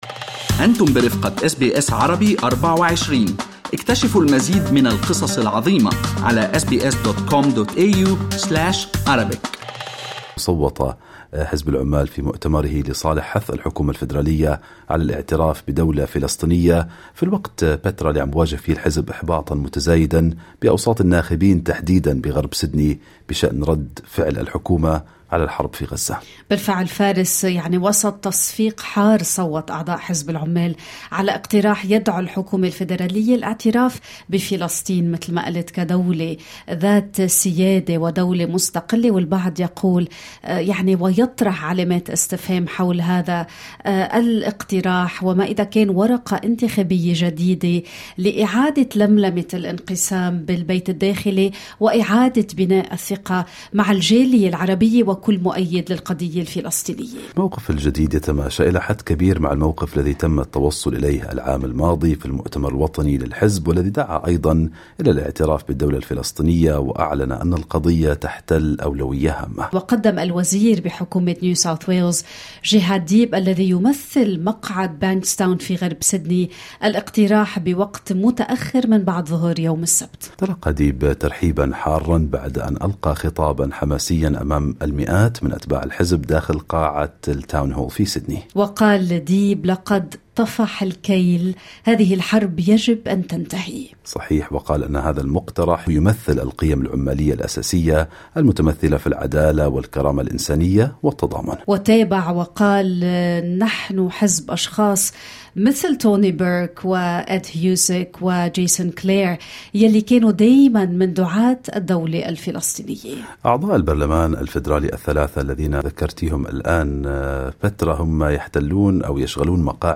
"خطوة مهمة": خضر صالح يروي كواليس مقترح مؤتمر العمال الاعتراف بدولة فلسطينية